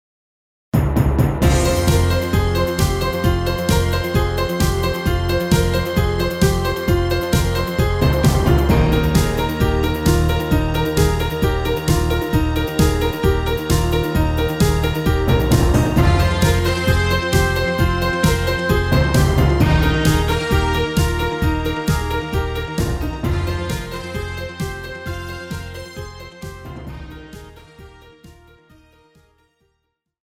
KARAOKE/FORMÁT:
Žánr: Pop